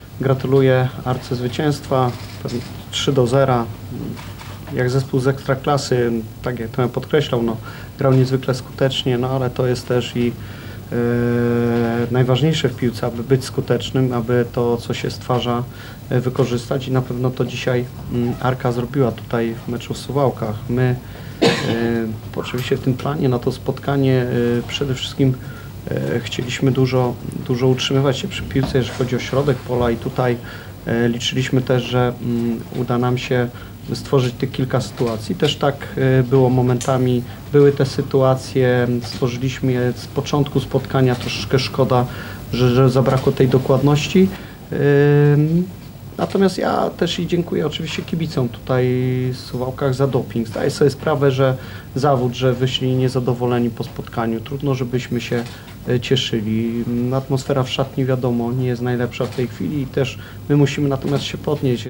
na pomeczowej konferencji prasowej